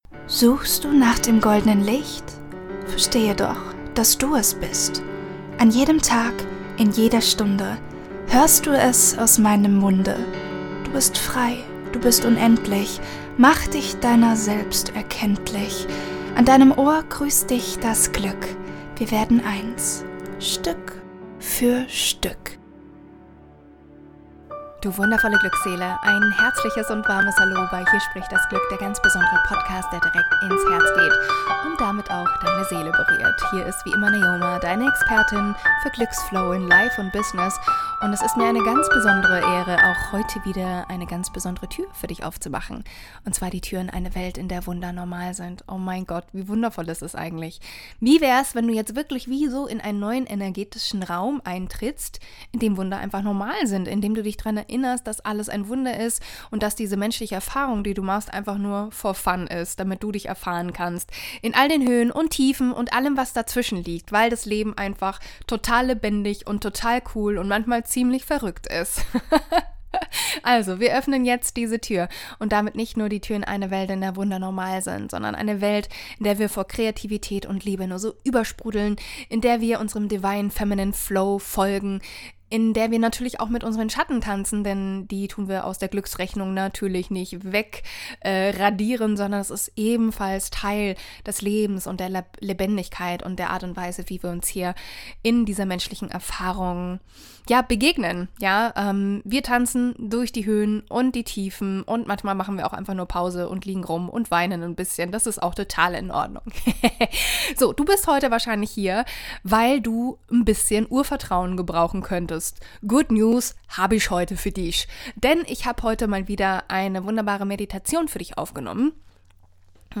Diese Meditation wird dich daran erinnern, dass du IMMER getragen bist.
Verbinde_dich_wieder_mit_deinem_natuerlichen_Urvertrauen_Meditation.mp3